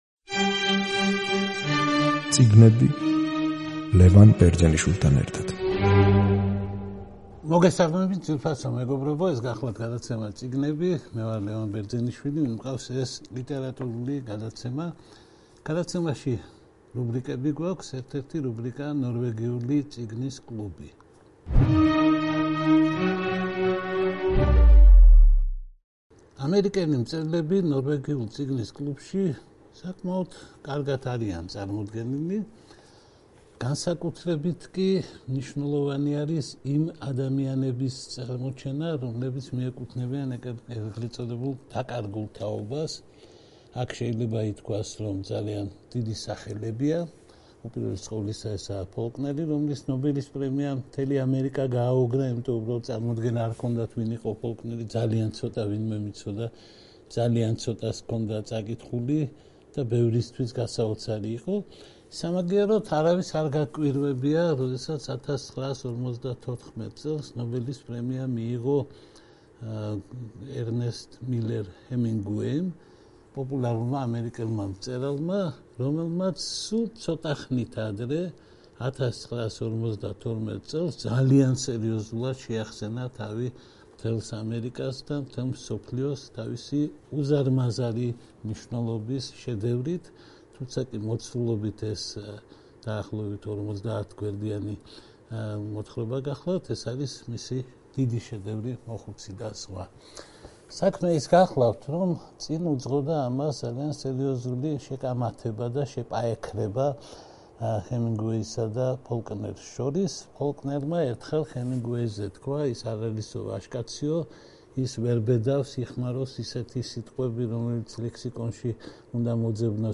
გადაცემა „წიგნები“ რუბრიკით „ნორვეგიული წიგნის კლუბი“ გთავაზობთ საუბარს ერნესტ მილერ ჰემინგუეის ერთ-ერთ უკანასკნელ შედევრზე, რომელმაც მას ამერიკაში პულიცერისა და მსოფლიოში ნობელის პრემია მოუტანა, გენიალურ მოთხრობაზე „მოხუცი და ზღვა“.